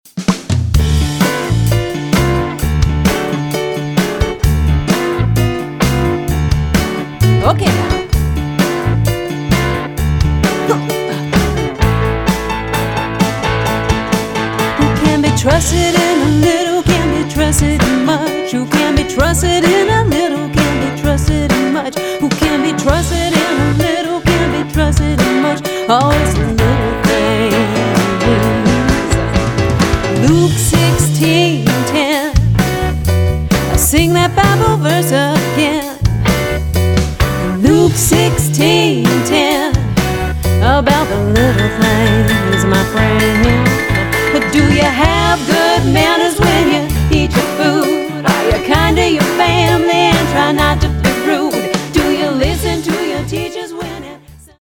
eclectic, contemporary Bible verse songs